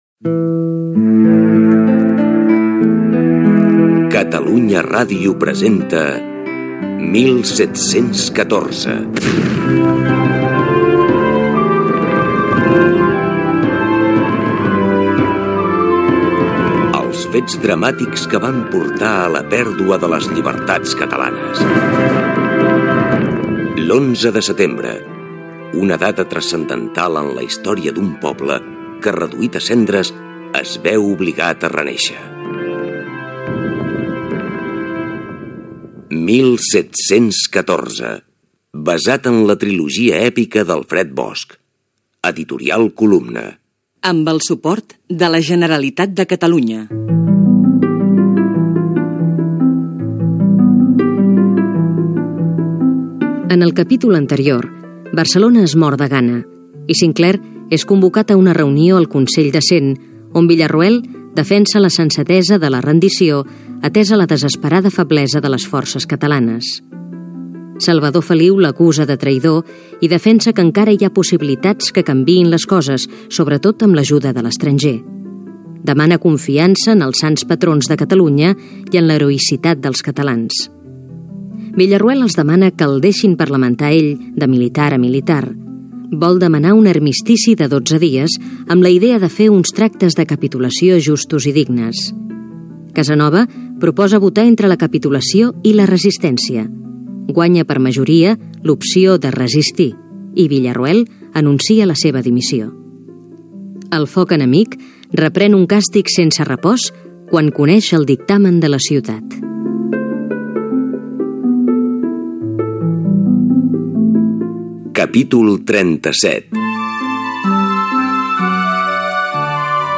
Sèrie radiofònica basada en la trilogia "1714", d'Alfred Bosch. Capítol 37.
Ficció